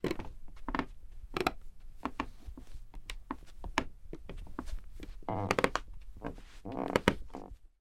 foley-footsteps-creaky-attic-floor-003